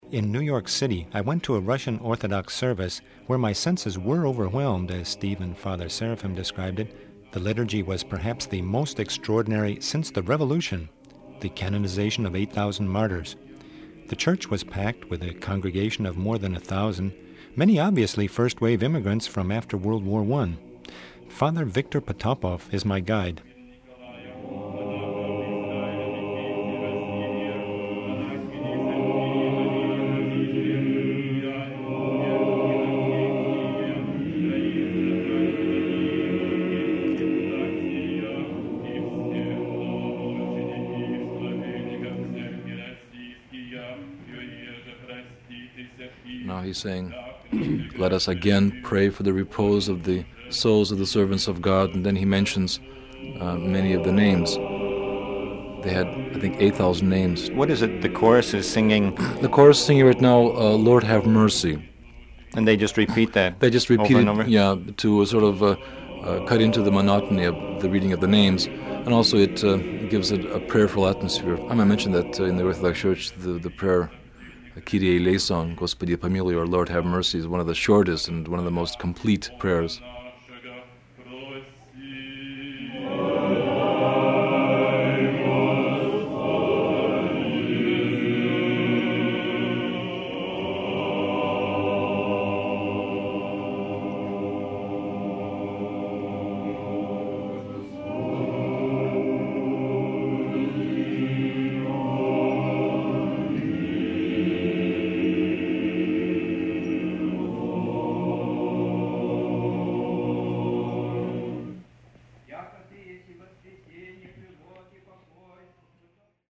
RUSSIAN   Bell ringing; balalaika; & a major liturgy
Russian.mp3